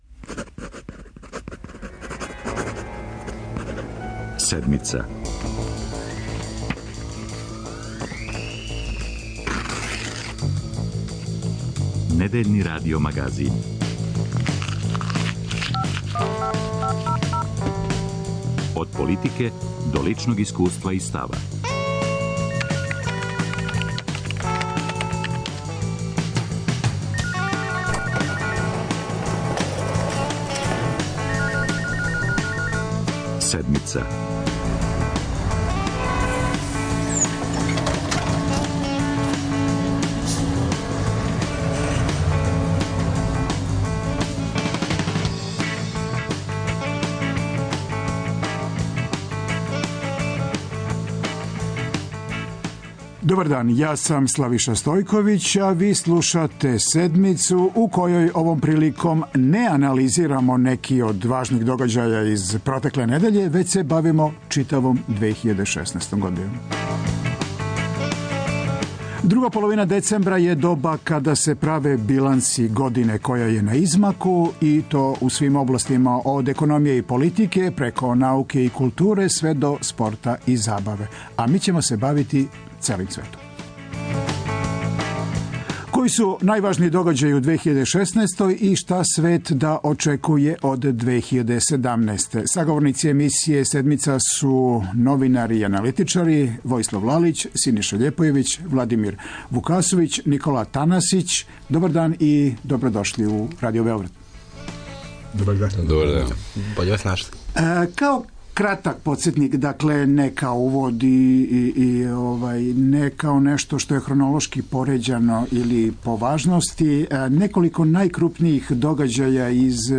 преузми : 26.48 MB Седмица Autor: разни аутори Догађаји, анализе, феномени.